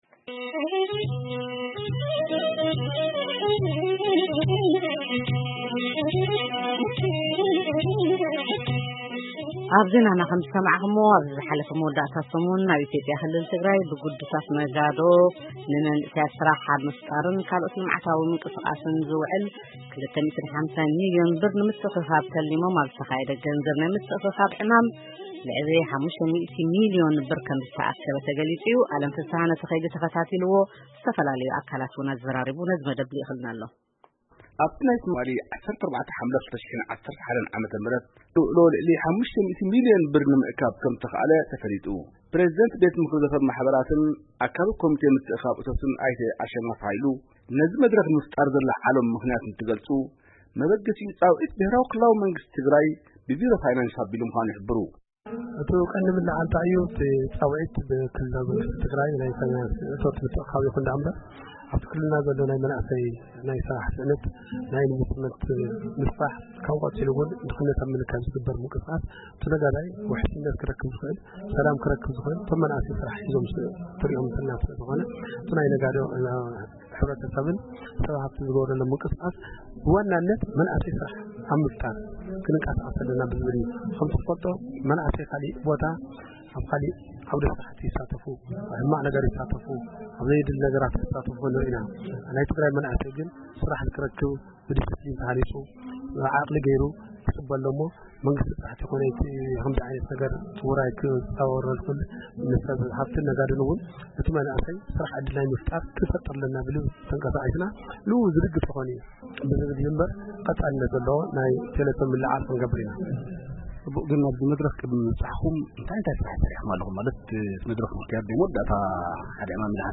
ቃለ መጠይቕ